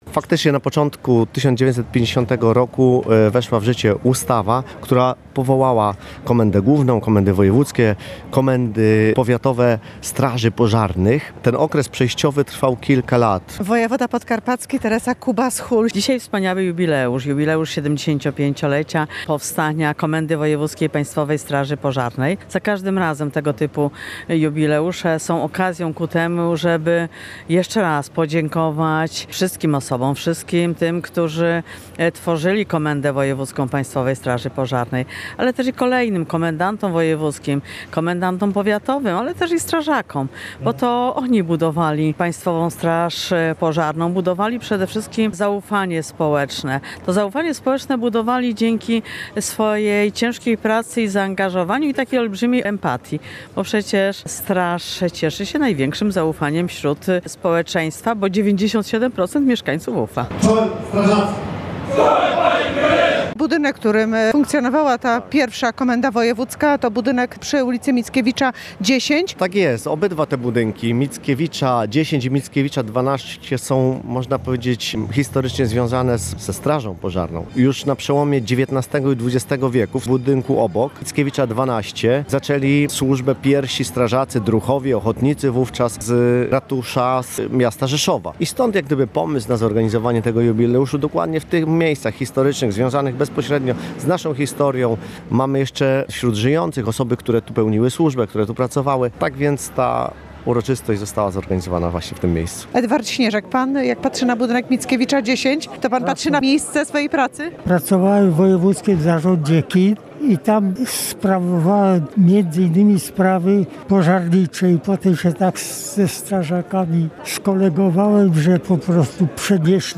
W Rzeszowie przy Placu Mickiewicza 10 odbył się apel inaugurujący obchody Jubileuszu 75-lecia powstania Komendy Wojewódzkiej Państwowej Straży Pożarnej w Rzeszowie.